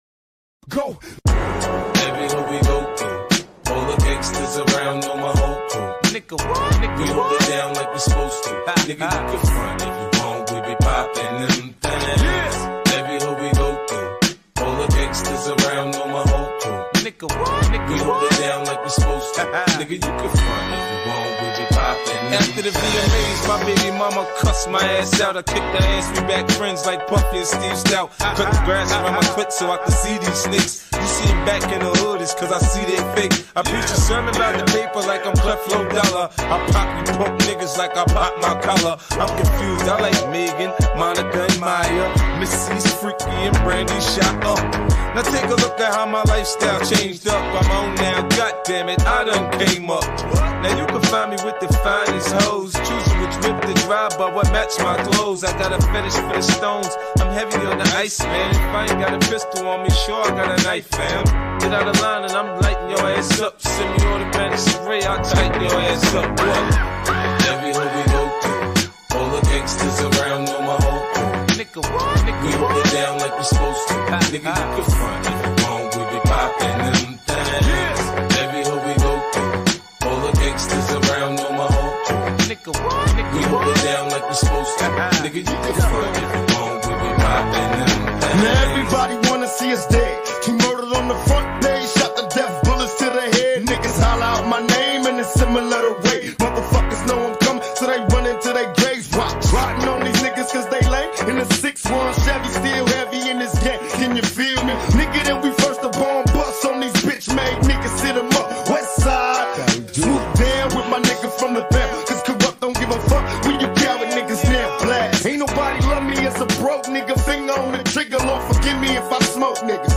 ژانر: رپ خارجی